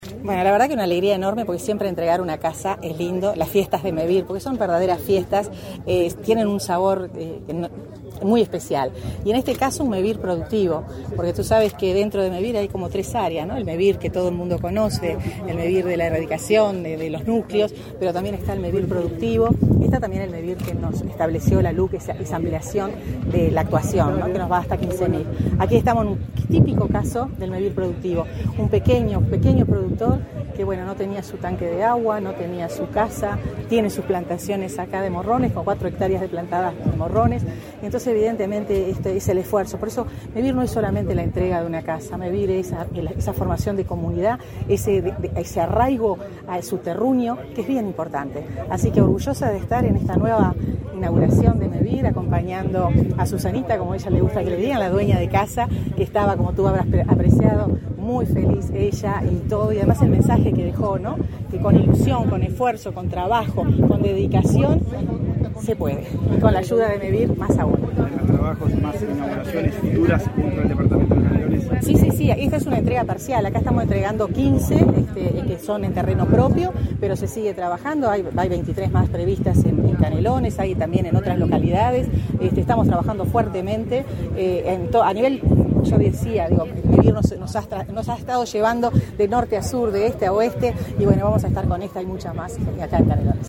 Declaraciones a la prensa de la ministra de Vivienda y Ordenamiento Territorial, Irene Moreira
Tras el evento, la ministra Moreira realizó declaraciones a la prensa.